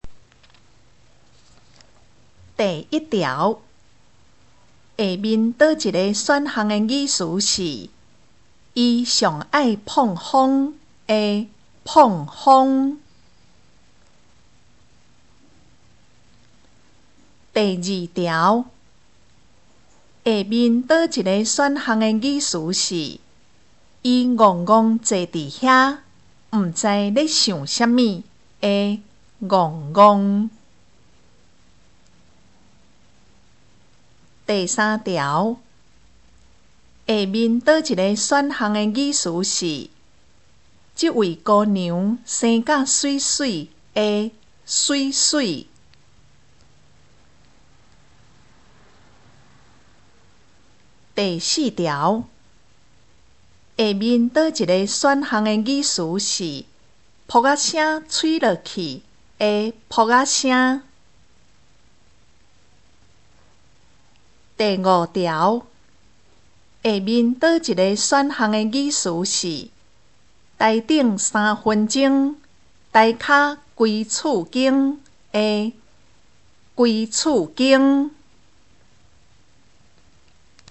【國中閩南語2】單元評量(1)聽力測驗mp3